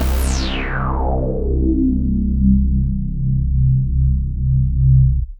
I’m also providing a bass sound I made with my Reface CS and a random drum break.